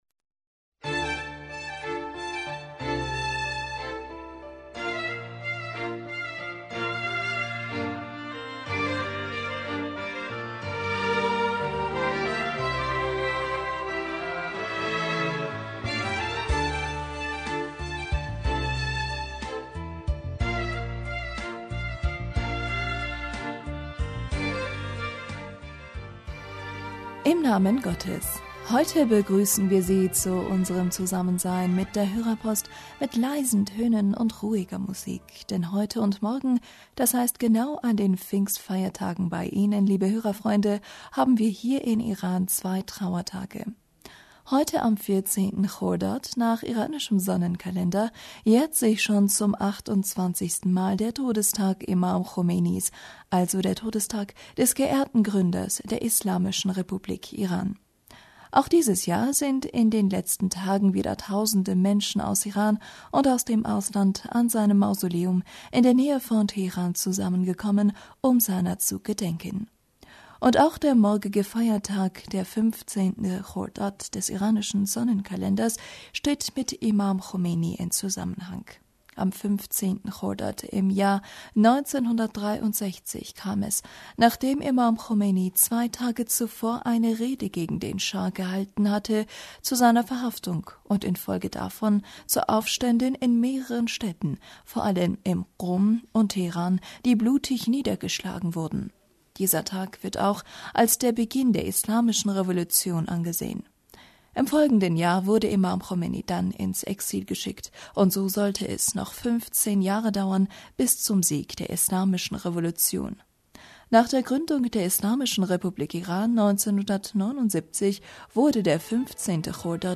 Hörerpostsendung am 4.Juni 2017 - Bismillaher rahmaner rahim -...
Heute begrüßen wir Sie zu unserem Zusammensein mit der Hörerpost mit leisen Tönen und ruhiger Musik, denn heute und morgen, d.h. genau an den Pfingstfeiertagen bei Ihnen liebe Hörerfreunde, haben wir hier in Iran zwei Trauertage.